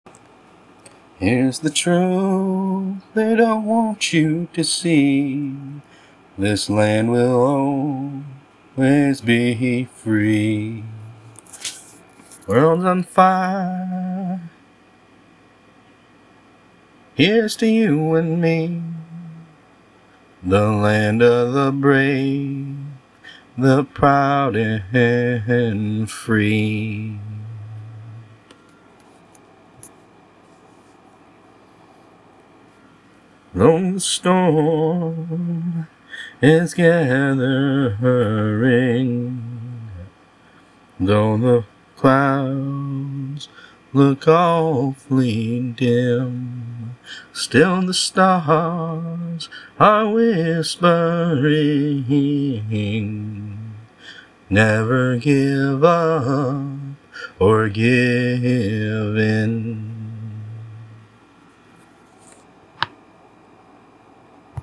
I ain't much of a singer, and this isn't a guitar piece, but I have message for all of you.
Just came to me, tune, and lyrics, practically burst out of me fully formed moments after praying. Had to scramble to record it before I forgot it.